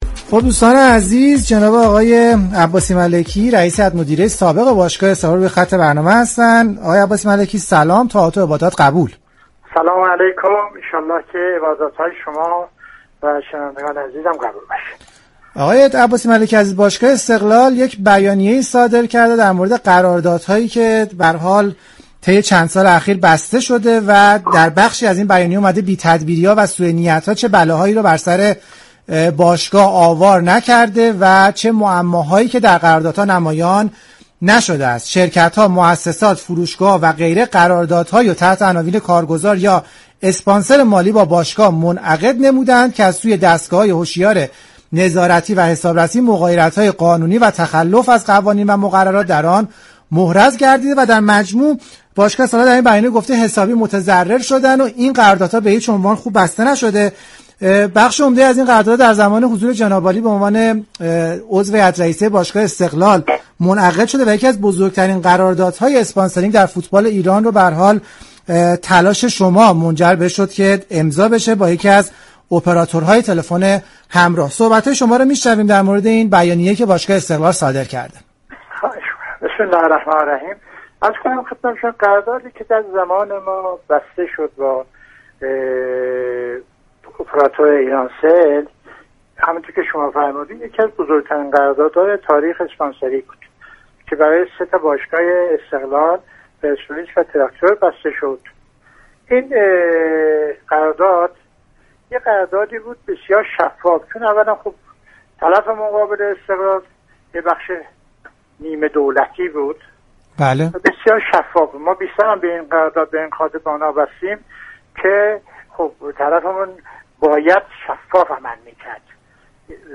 برنامه زنده